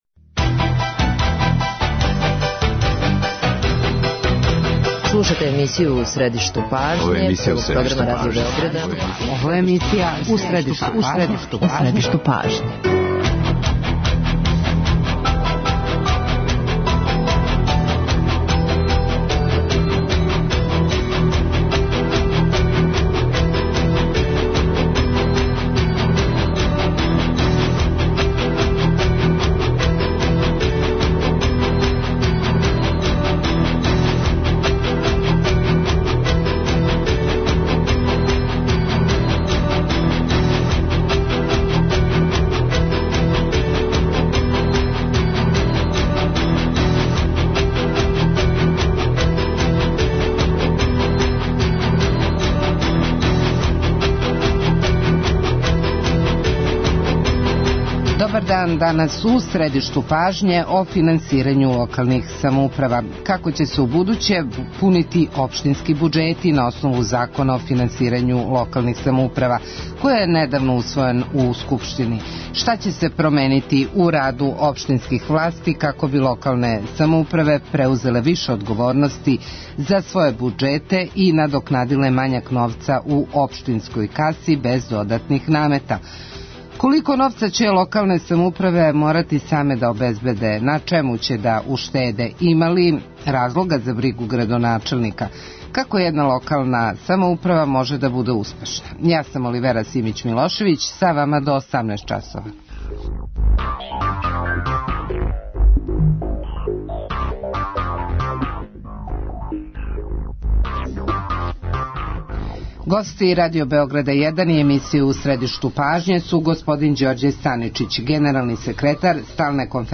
Како решавају свакодневне проблеме и раде без дугова чућемо од председника неколико општина у Србији које су издвојене као примери добрих локалних самоуправа: Јелене Трифуновић, председнице општине Сврљиг, Радосава Васиљевића, председника општине Нова Варош и градоначелника Пирота Владана Васића.